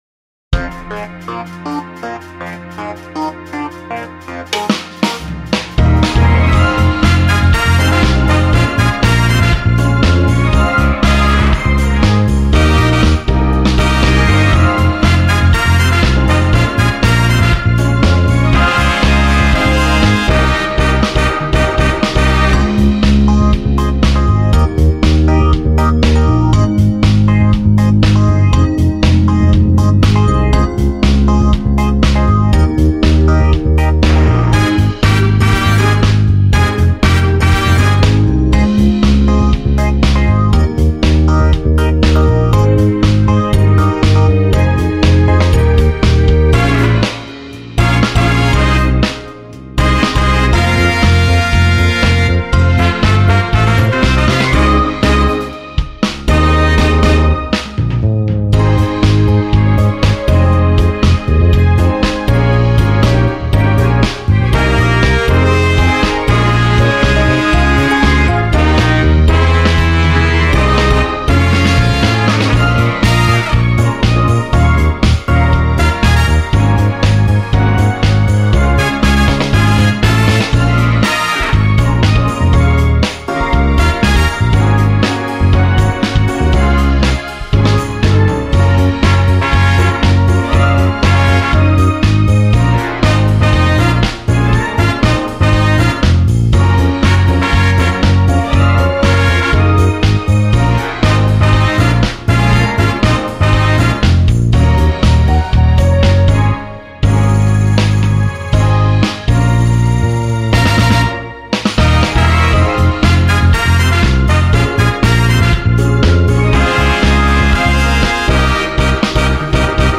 Drum/Bass/Piano/E.Piano
Drum:Int Rock Kit Bass:Electric Bass/Piano:Rock Experience E.Piano:Delicate DX